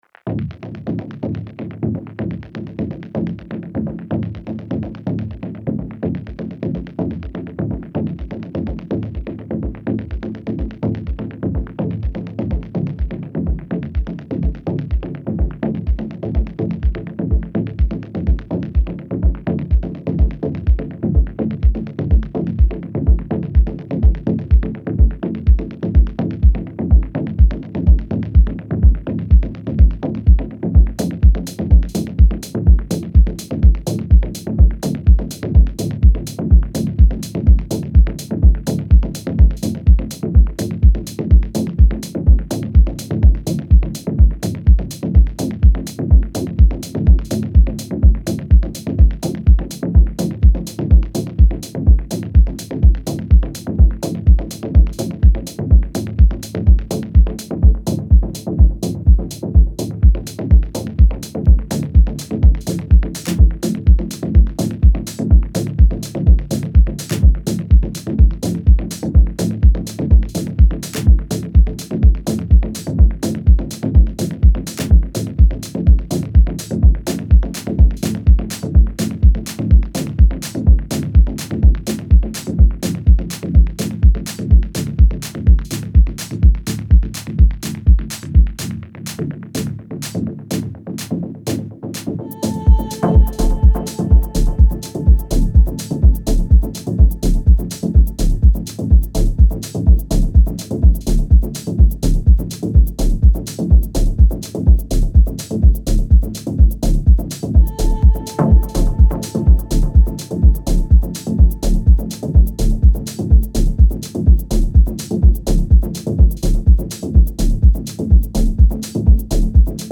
FM8